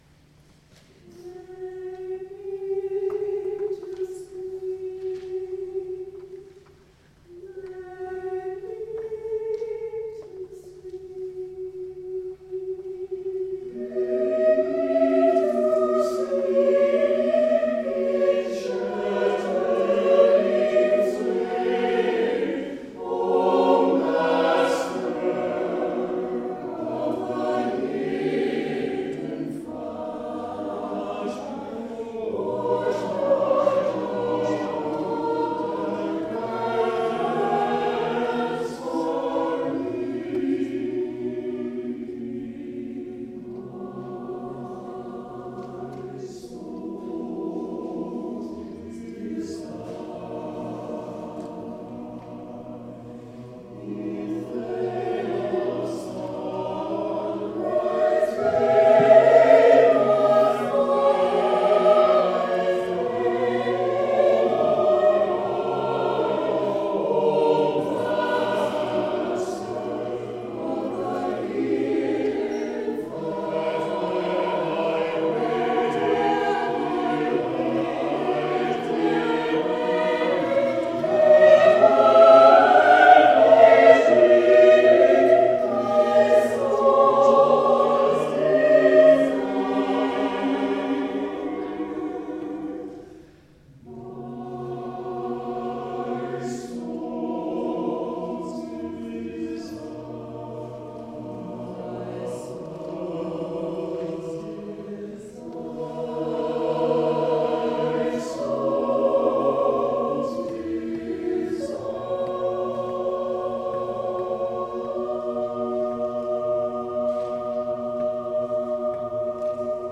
SATB chorus, unaccompanied
SATB chorus unaccompanied